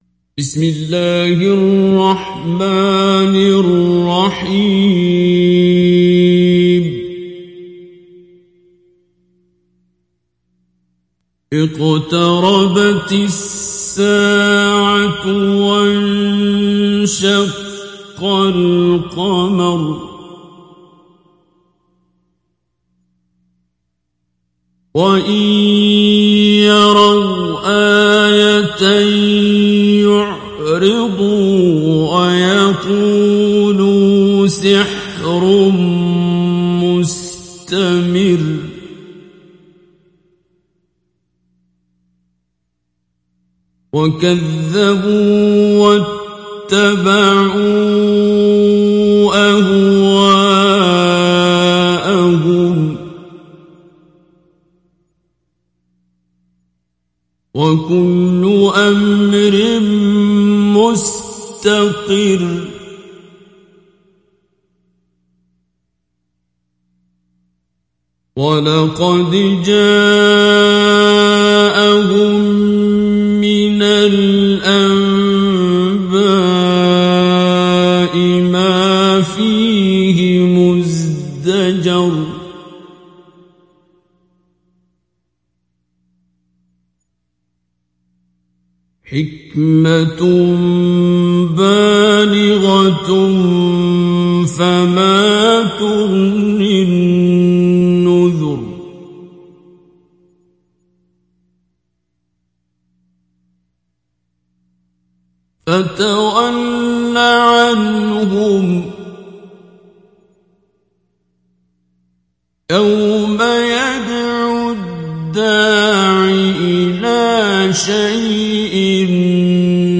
Mujawwad